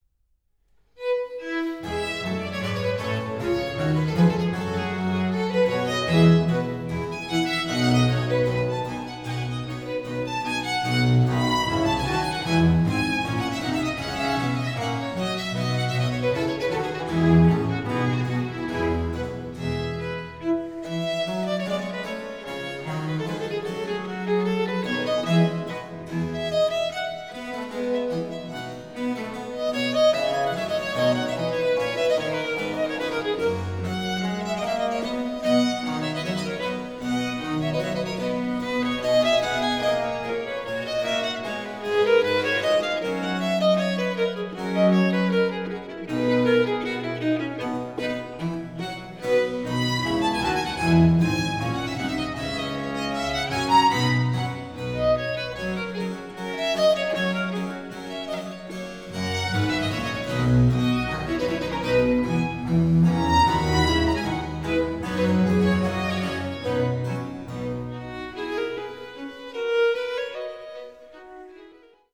Ausschnitt-Concerto-F-Dur.mp3